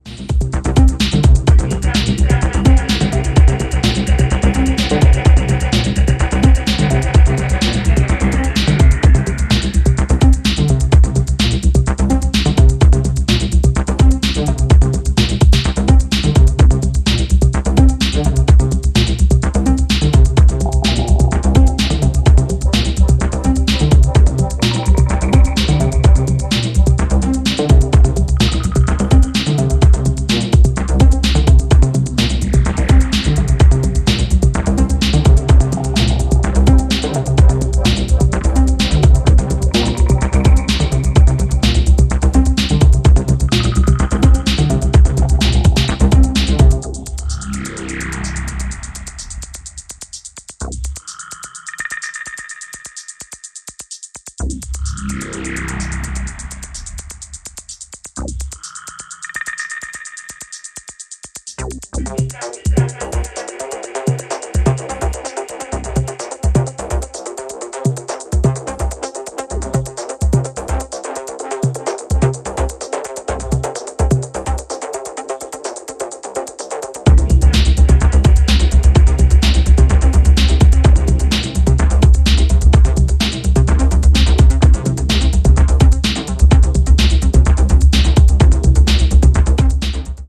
ジャンル(スタイル) HOUSE / TECHNO